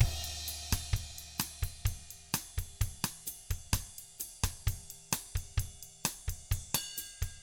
129BOSSAT1-L.wav